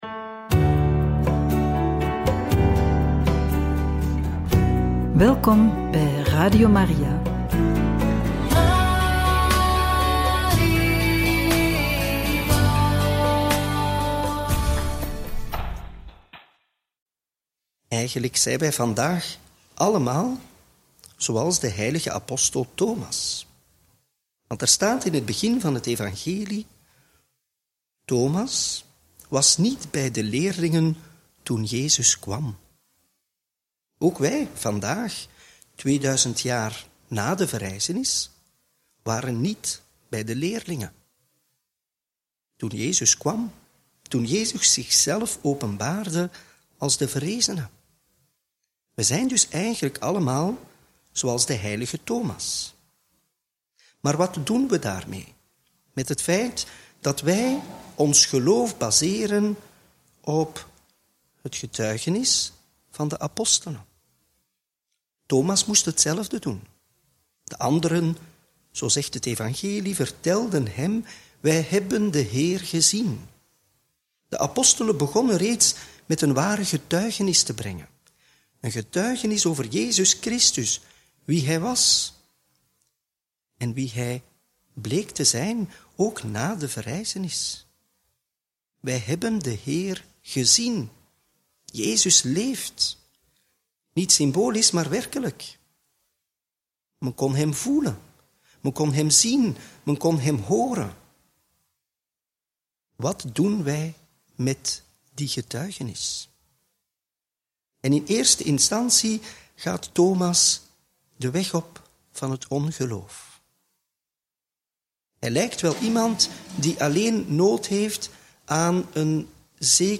Homilie bij het Evangelie van donderdag 3 juli 2025 – Feest van de Heilige Thomas – Joh 20, 24-29